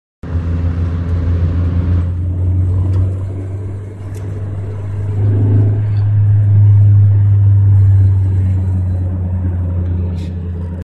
Nene das ist ein rasseln/klirren wie wennde schrauben in ner blechdose schuettelst
Mir ist aufgefallen das es relativ leise ist wenn der motor kalt ist aber sobald er warm wird, wird es richtig laut
Kalter motor 3ter gang 3000touren